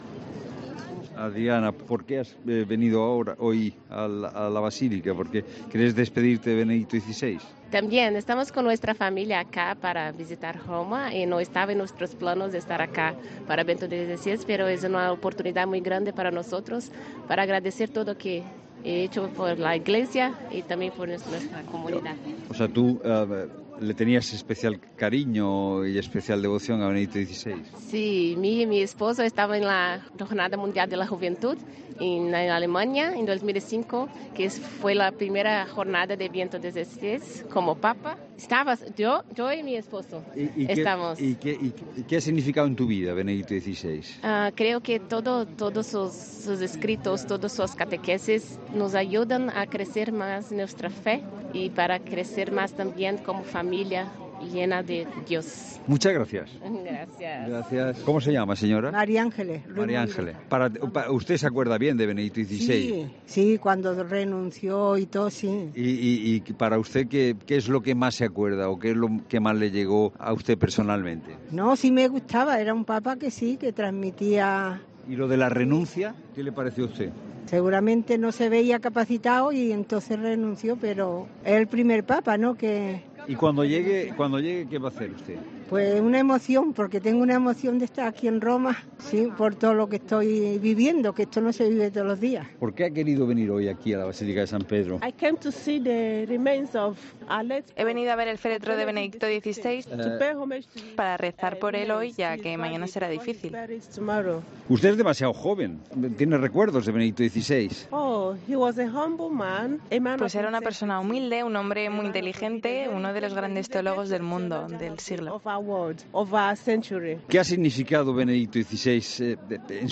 AUDIO: El equipo de 'La Tarde' te cuenta desde Roma todos los detalles del tercer y último día de la capilla ardiente del Papa Benedicto XVI